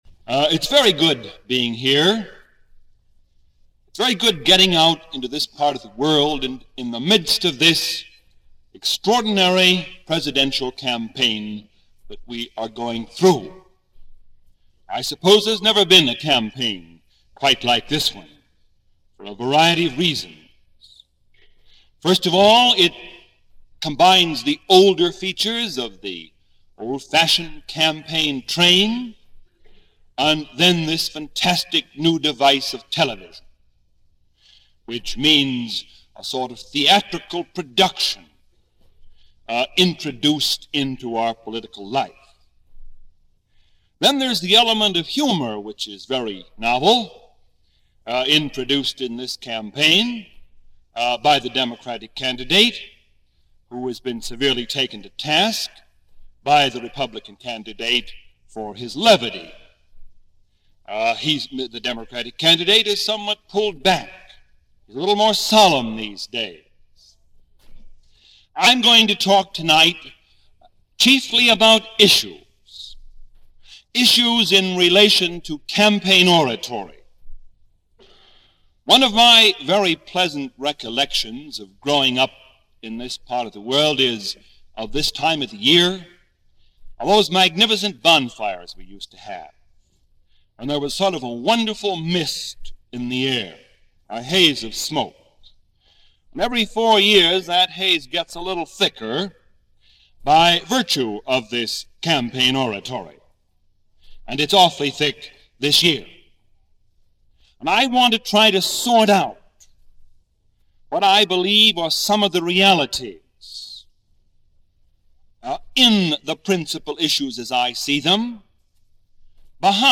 Election '52 - Heading To The Polls - Past Daily After Hours Reference Room - recorded April 1952 - Illinois University Lecture series.
Marquis-Childs-Lecture-1952-edited.mp3